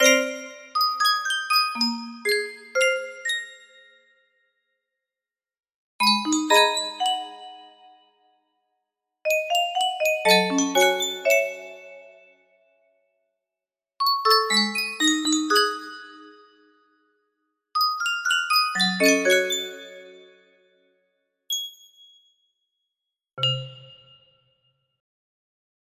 l1 music box melody
Full range 60